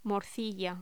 Locución: Morcilla
voz